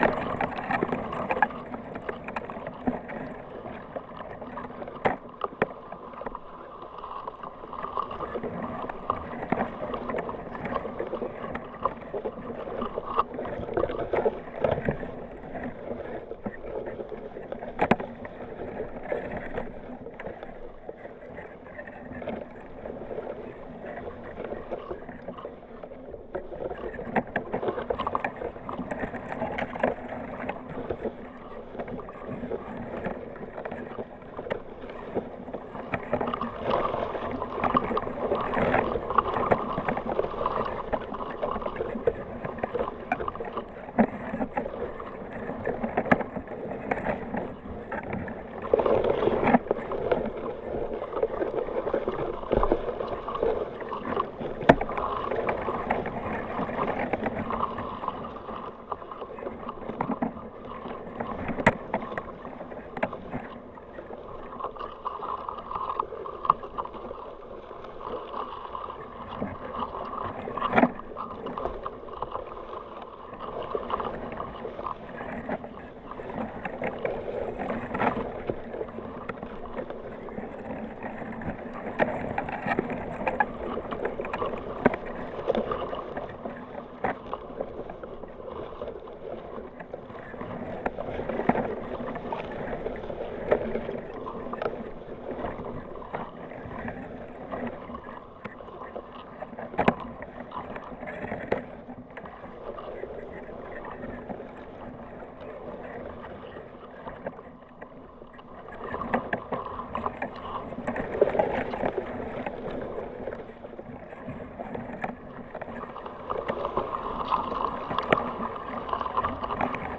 Cañada. Reed
Sonido del movimiento de una cañada al ritmo de la Tramuntana con tres micrófonos de contacto de Audiotalaia.
[ENG] Sound movement of a reed, thanks to Tramuntana wind. With three Audiotalaia contact microphones.
canyes.wav